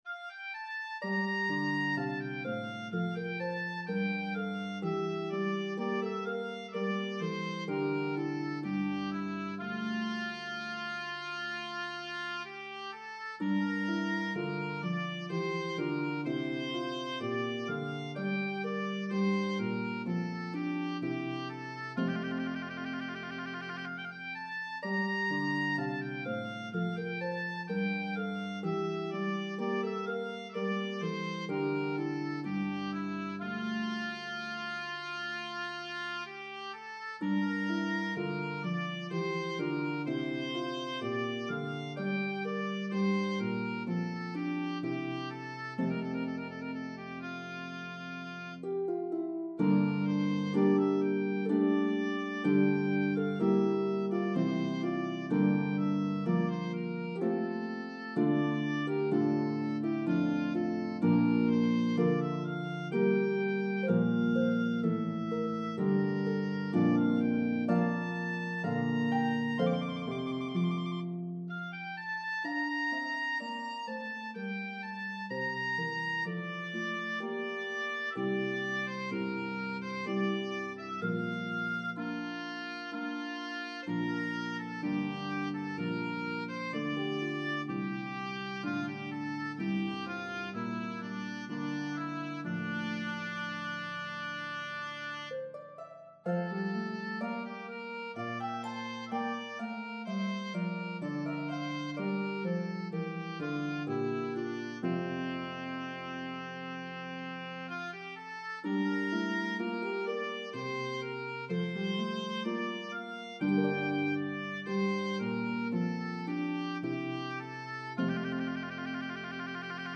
Italian Baroque style
lovely slow air